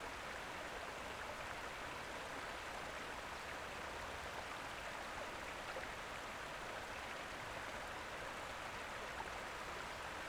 pond-ambience-2.wav